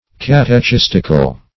Search Result for " catechistical" : The Collaborative International Dictionary of English v.0.48: Catechistic \Cat`e*chis"tic\ (-k[i^]s"t[i^]k), Catechistical \Cat`e*chis"tic*al\, a. Of or pertaining to a catechist or to a catechism.